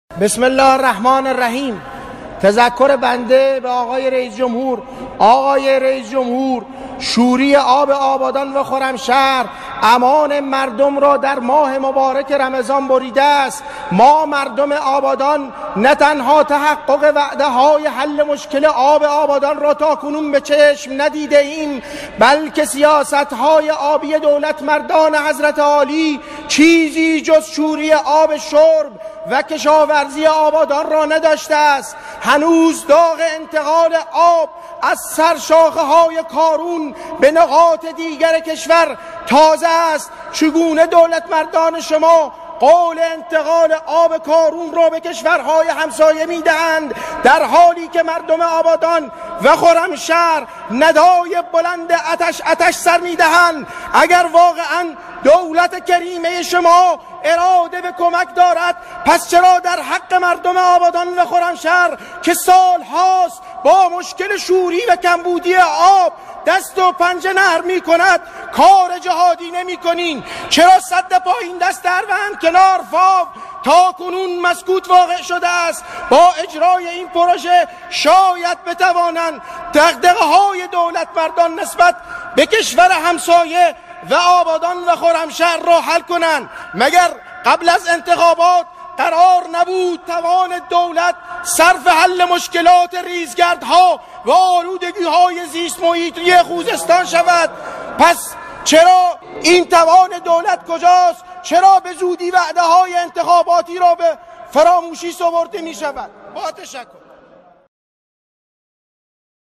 ندای عطش مردم آبادان در صحن علنی مجلس